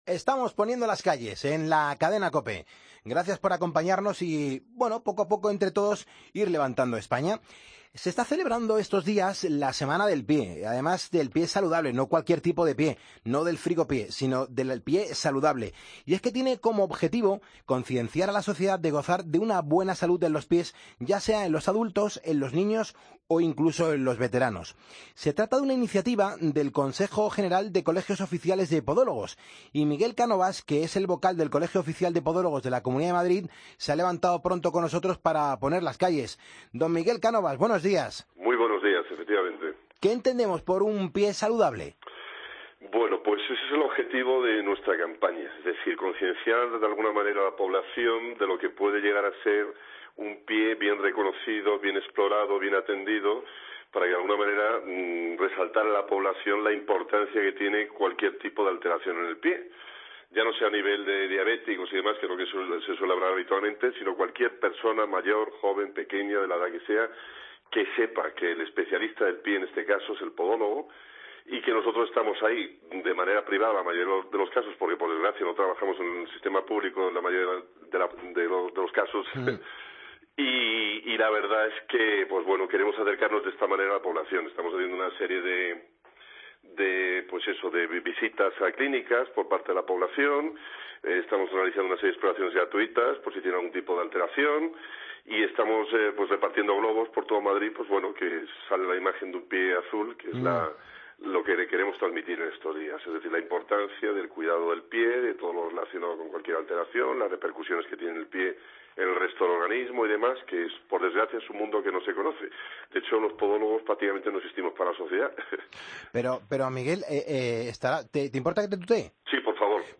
AUDIO: Lo organiza el Consejo General de Colegios Oficiales de Podólogos.